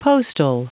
Prononciation du mot postal en anglais (fichier audio)
Prononciation du mot : postal